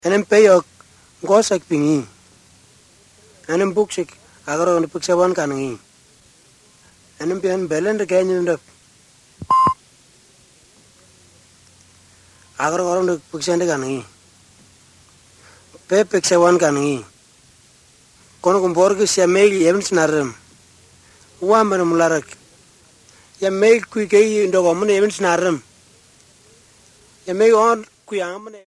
These are recorded by mother-tongue speakers
Bible Overview, Bible Stories, Discipleship